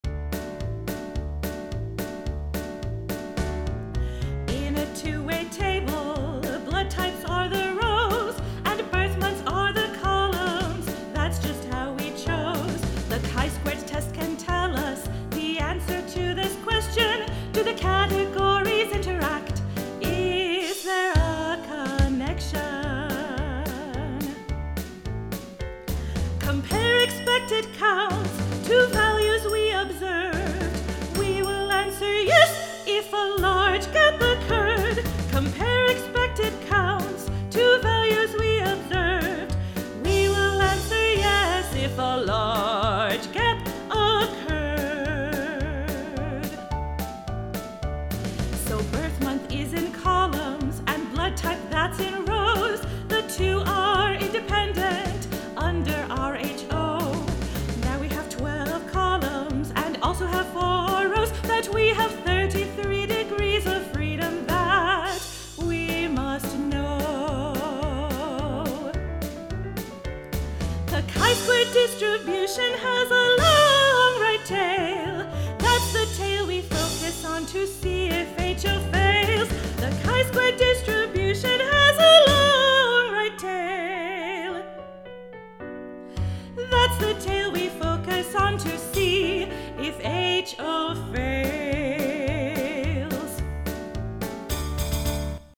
Chi-squared Dance (Studio Version).mp3